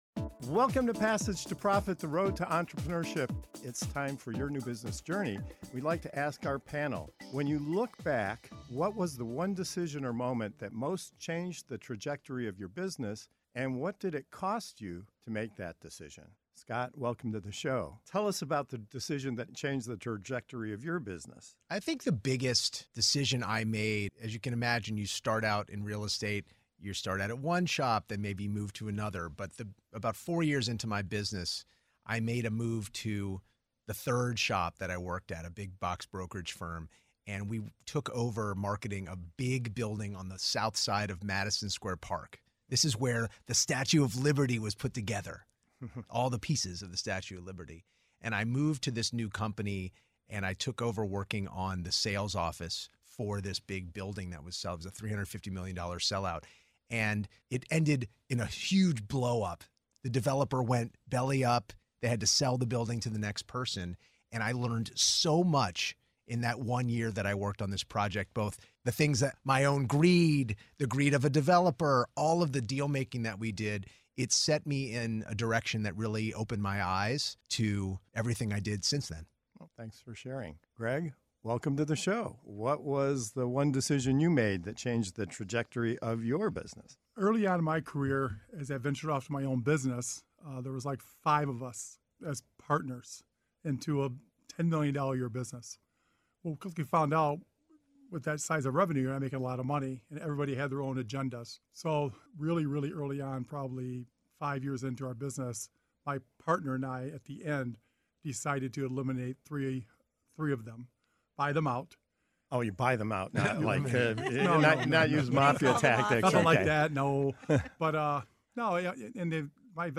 In this segment of "Your New Business Journey", entrepreneurs share the pivotal moments that reshaped their paths—from a high-stakes $350M real estate deal that ended in collapse, to the hard-earned lessons of buying out partners in a growing company, to reinventing a business after COVID by launching a podcast studio, and even stepping out from behind the scenes into the public spotlight. It’s an honest, behind-the-curtain look at the risks, failures, and bold moves that define entrepreneurial success—and the real cost of making the decisions that matter most.